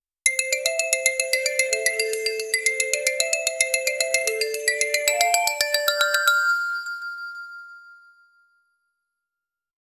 Royalty-free bells sound effects
short-08s-flutter-of-egyp-iopdxeni.wav